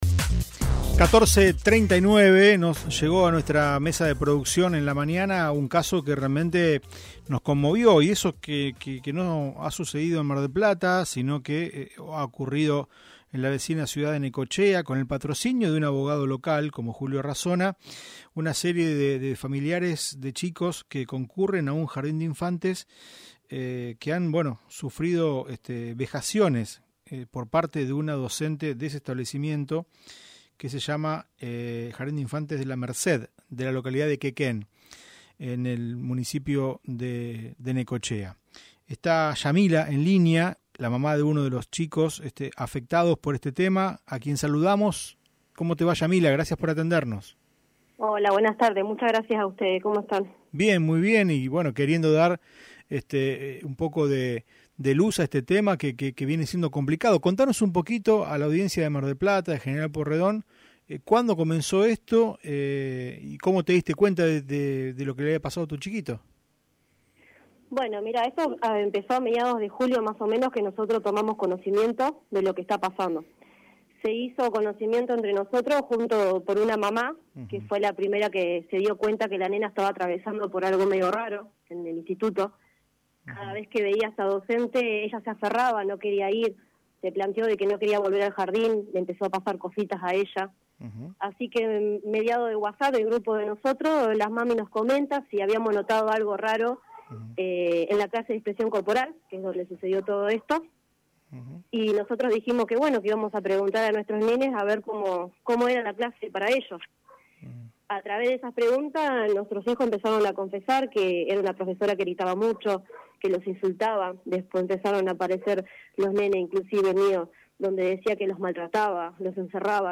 dialogó con Un Problema Menos de Radio Mitre Mar del Plata